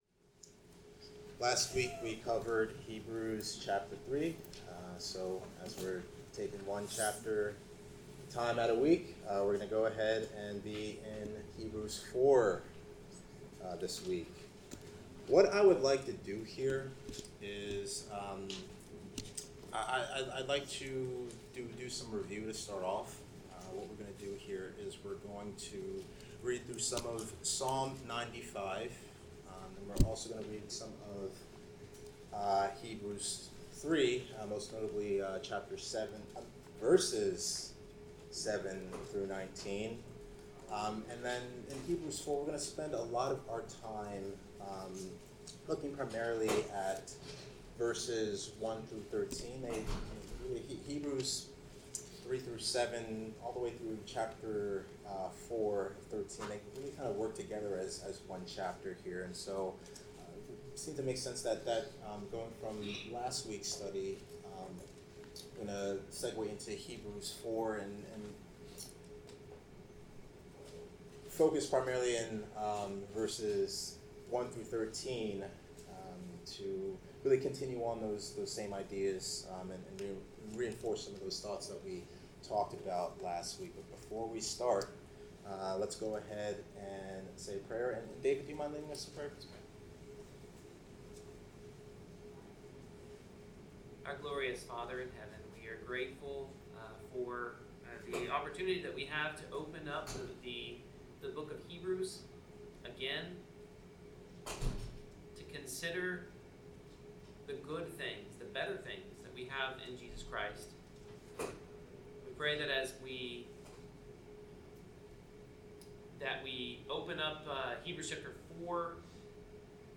Passage: Hebrews 4:1-13 Service Type: Bible Class